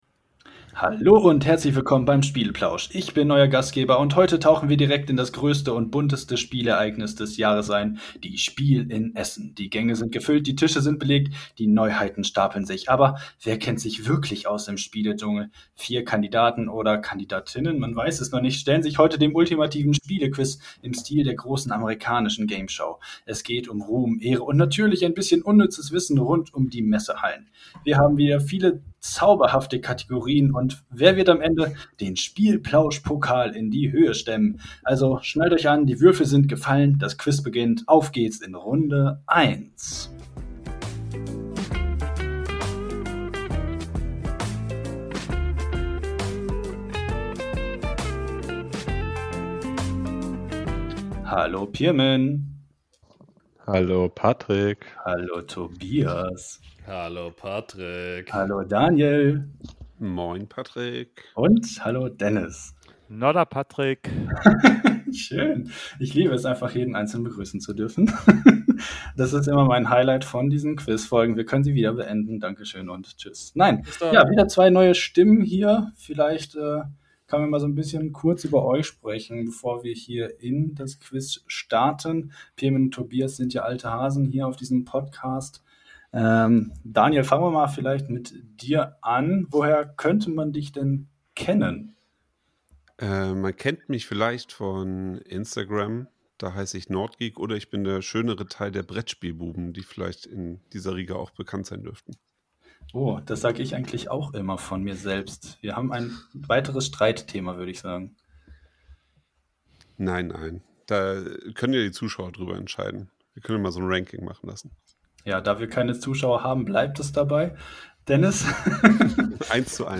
Wir Quizzen wieder um die Wette. Wer kennt sich mit der Messe Essen der SPIEL am besten aus?